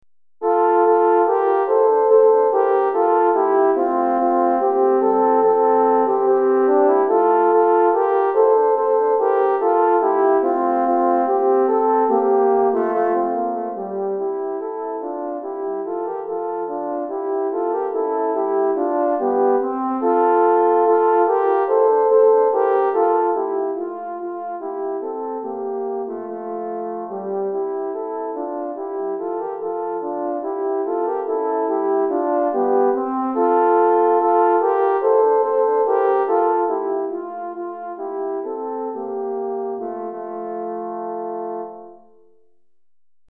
3 Cors